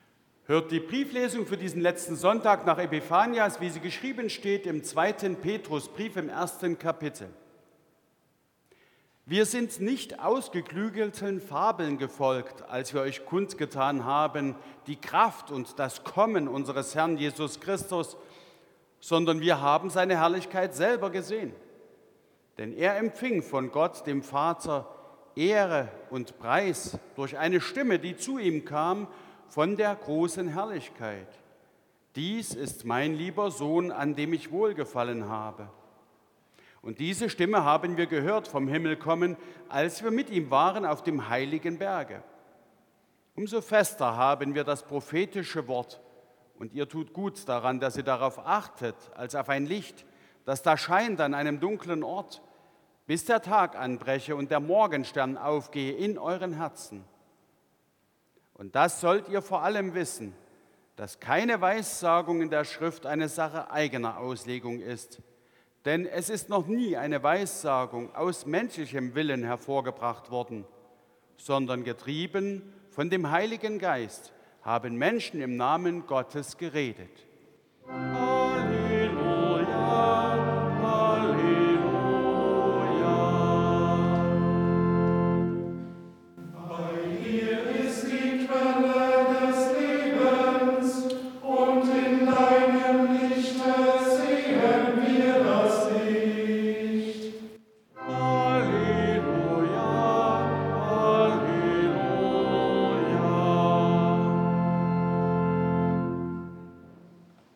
4. Epistellesung aus 2.Petrus 1,16-21 Evangelisch-Lutherische St. Johannesgemeinde Zwickau-Planitz
Audiomitschnitt unseres Gottesdienstes vom Letzten Sonntag nach Epipanias 2026.